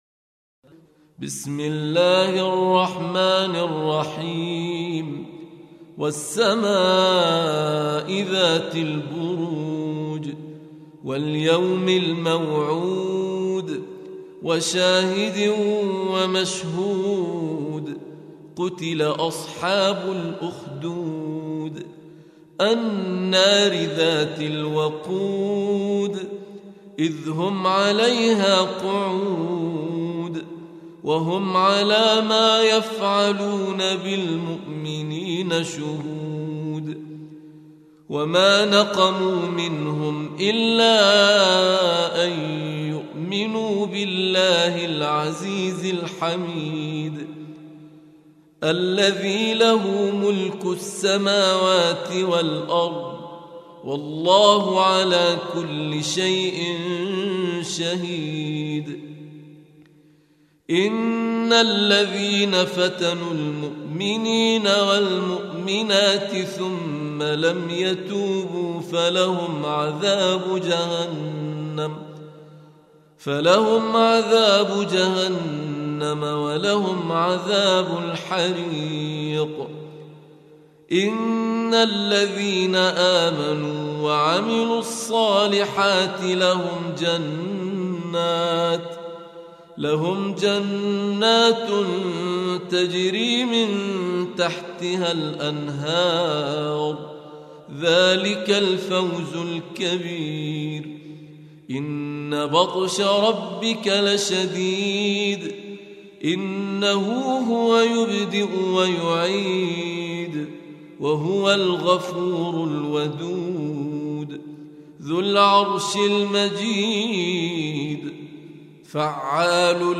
Surah Sequence تتابع السورة Download Surah حمّل السورة Reciting Murattalah Audio for 85. Surah Al-Bur�j سورة البروج N.B *Surah Includes Al-Basmalah Reciters Sequents تتابع التلاوات Reciters Repeats تكرار التلاوات